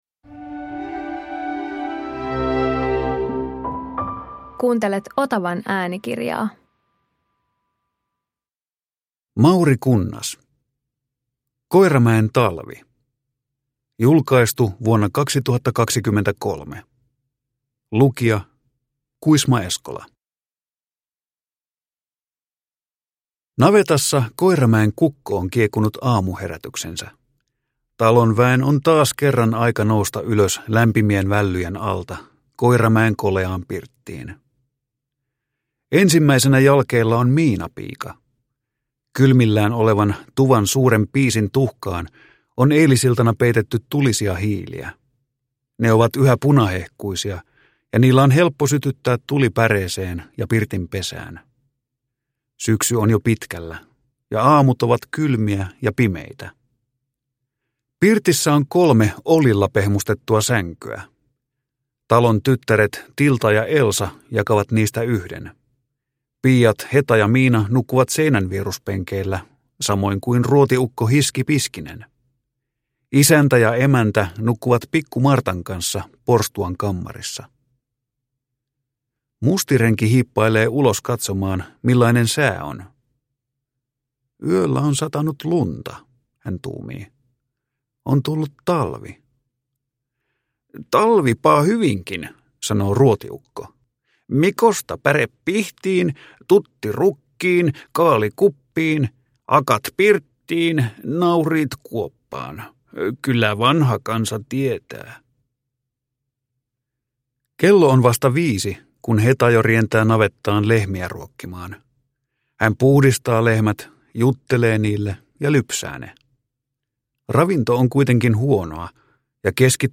Koiramäen talvi – Ljudbok